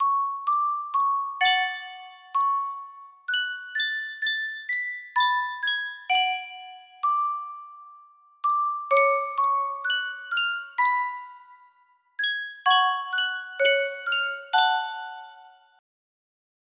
Sankyo Music Box - Csardas GTW music box melody
Full range 60